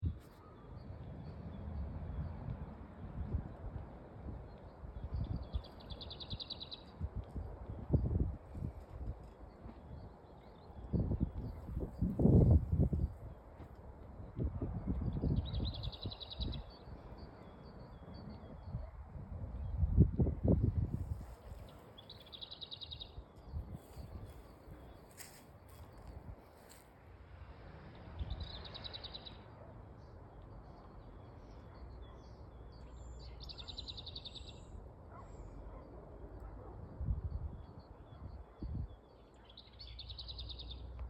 Birds -> Warblers ->
Lesser Whitethroat, Curruca curruca
StatusSinging male in breeding season